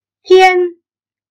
tiān - then Ngày